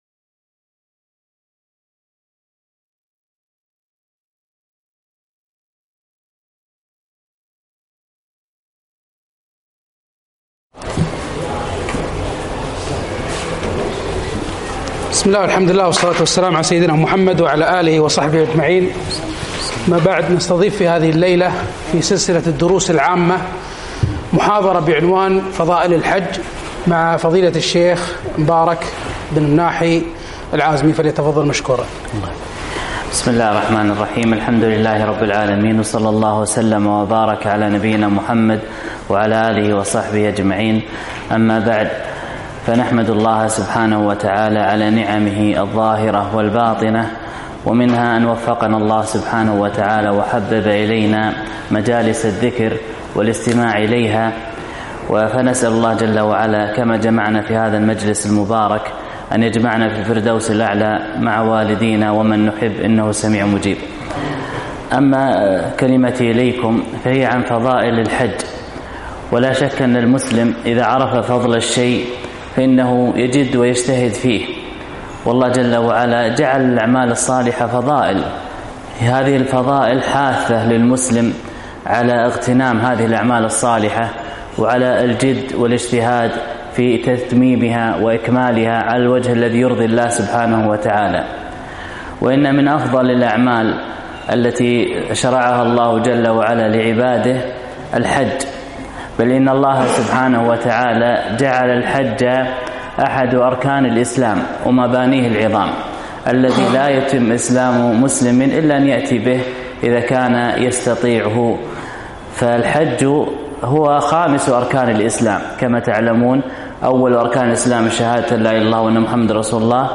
كلمة - فضائل الحج